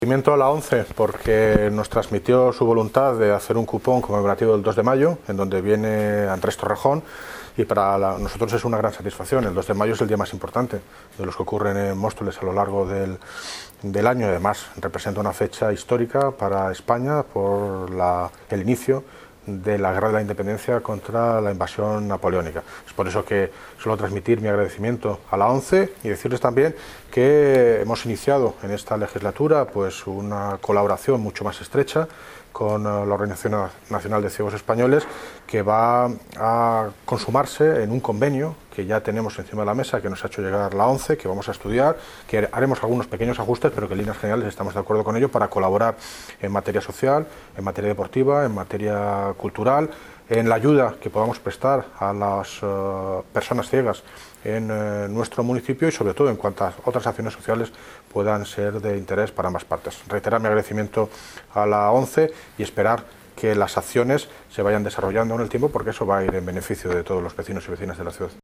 Audio - David Lucas (Alcalde de Móstoles) Sobre CUPON ONCE 2 DE MAYO